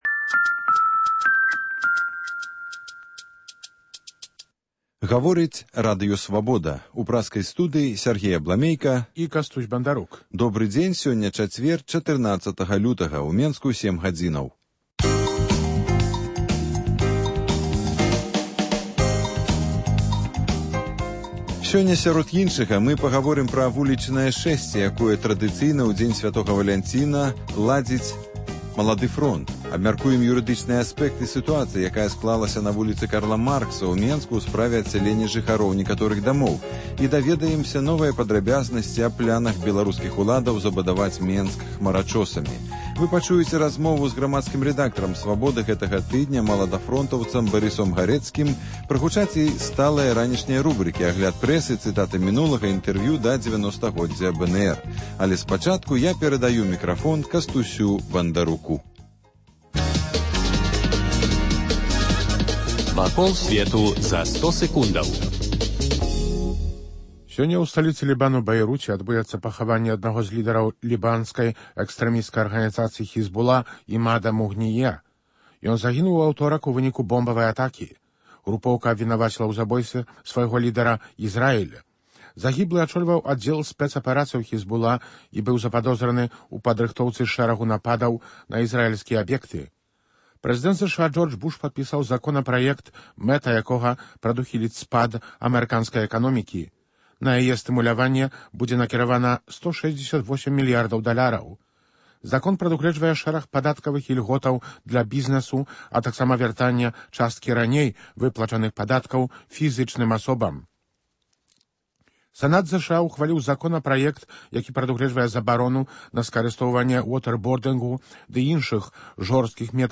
Ранішні жывы эфір